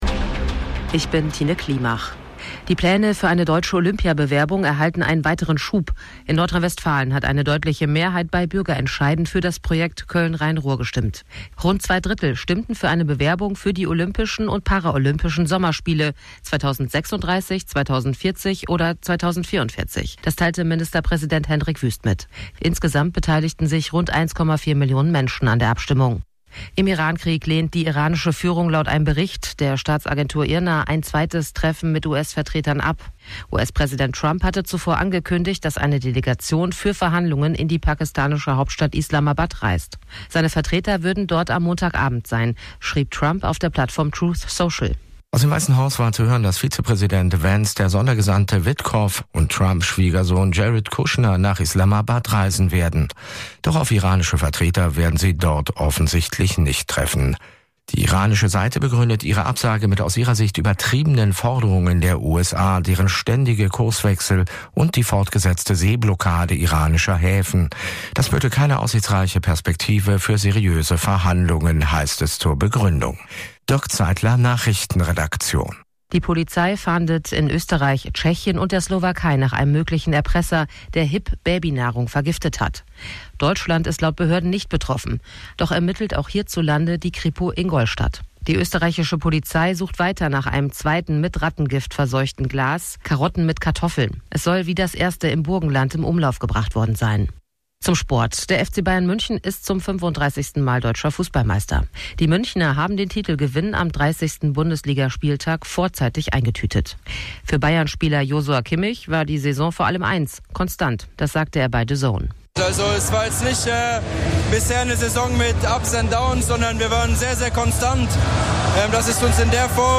Radio Hamburg Nachrichten vom 20.04.2026 um 01 Uhr